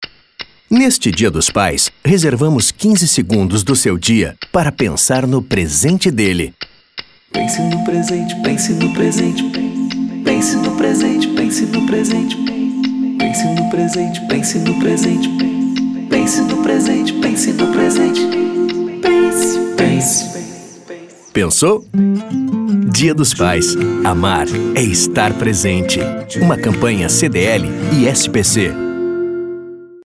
spot Download